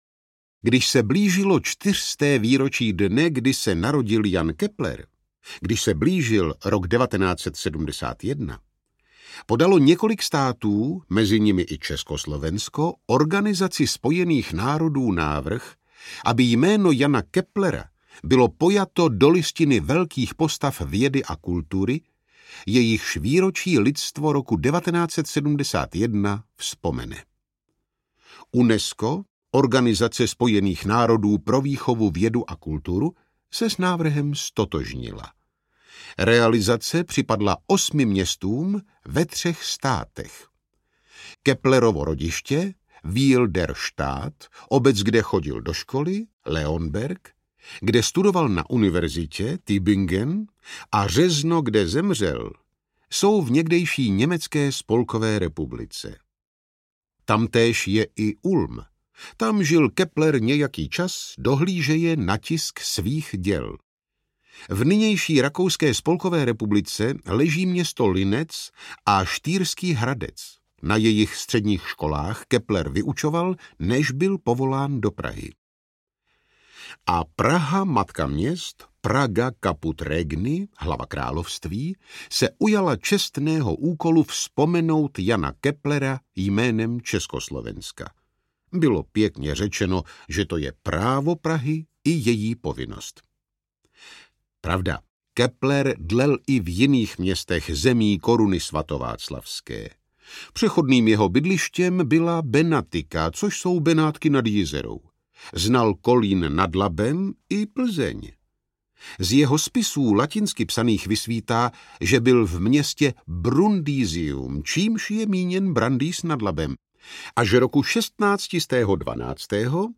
Syn čarodějnice audiokniha
Ukázka z knihy
Vyrobilo studio Soundguru.